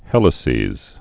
(hĕlĭ-sēz, hēlĭ-)